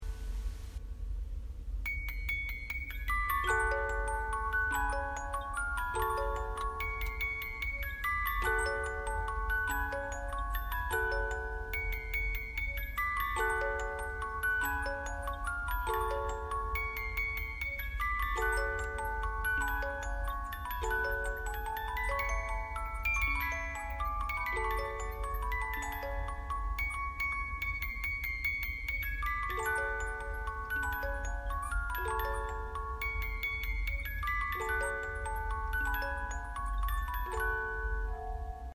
• Mouvement musical : 36 lames
Mélodies mouvement 36 lames :